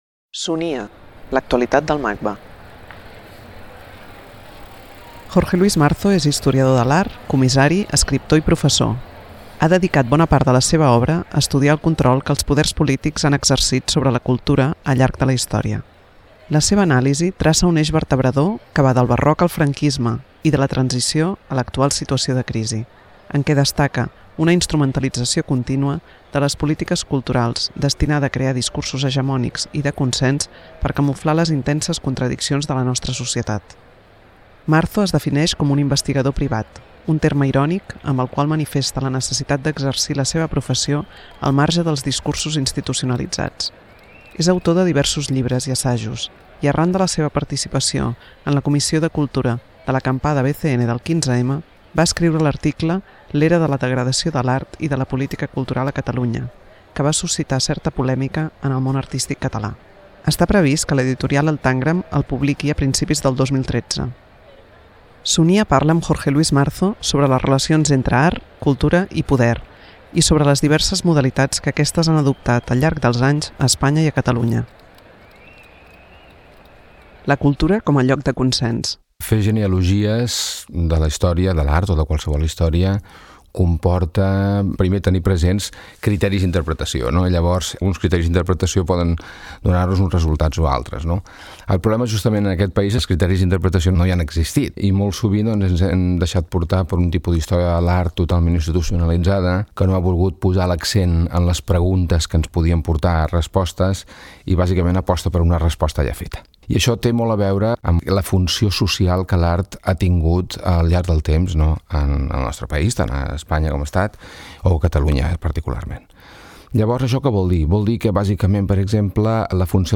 Gènere radiofònic Cultura